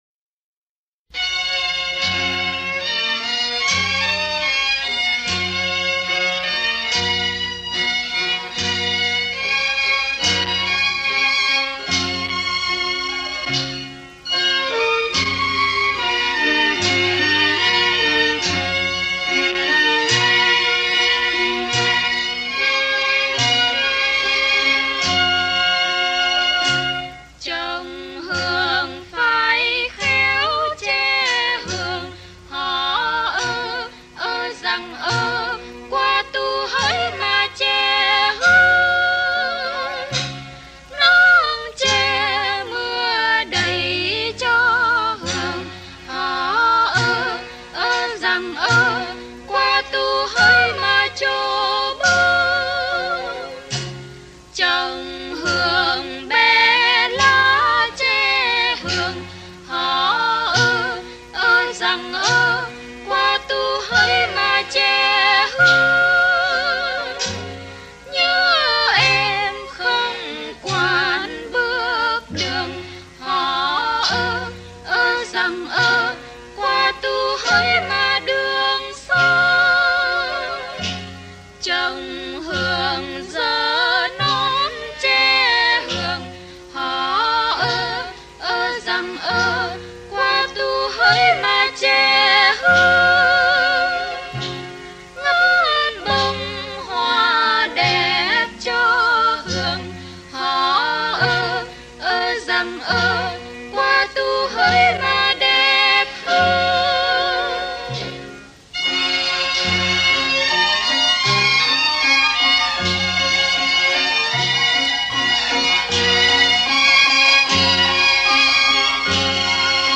Lý Che Hường – Dân ca
Trình Bày: Kim Tước & Mai Hương (pre 75)
Trên đây là bài Lý chè hương qua hai giọng ca nổi tiếng là Mai Hương, Kim Tước.